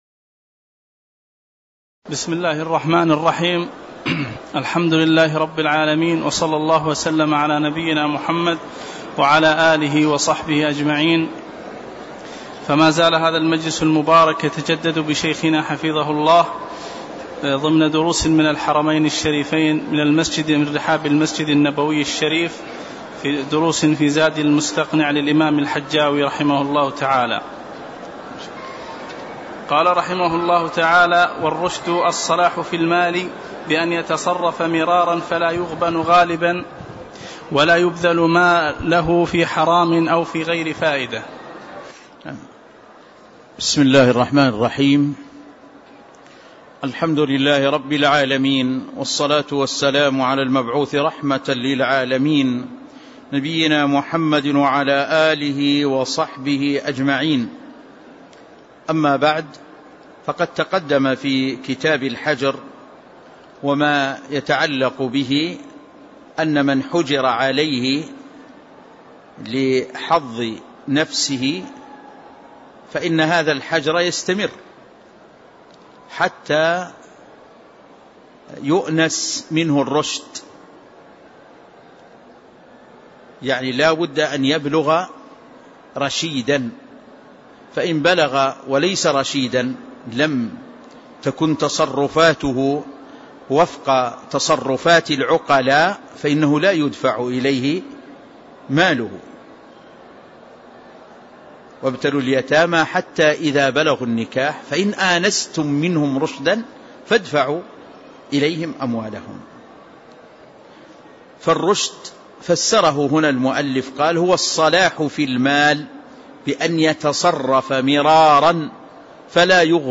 تاريخ النشر ٢٦ محرم ١٤٣٧ هـ المكان: المسجد النبوي الشيخ